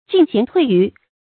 進賢退愚 注音： ㄐㄧㄣˋ ㄒㄧㄢˊ ㄊㄨㄟˋ ㄧㄩˊ 讀音讀法： 意思解釋： 進用賢能，黜退愚懦。